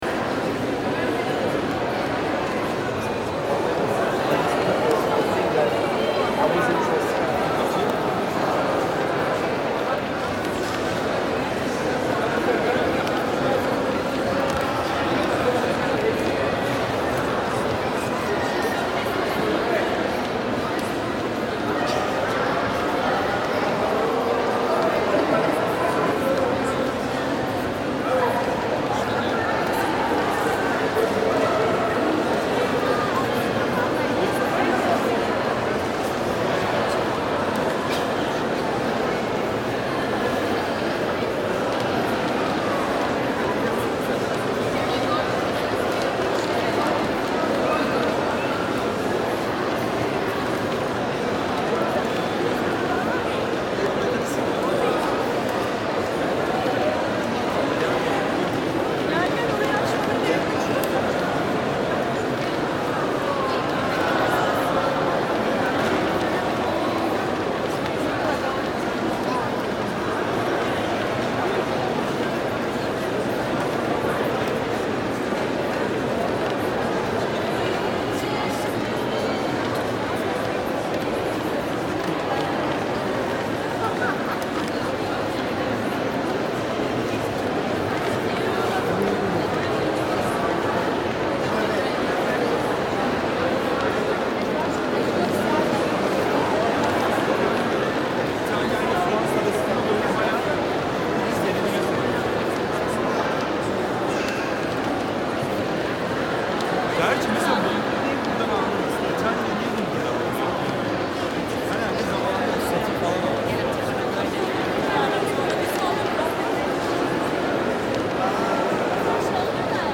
Разговоры, смех и восторг толпы людей для монтажа видео в mp3
13. Звук толпы в музее
zvuk-tolpy-v-muzee.mp3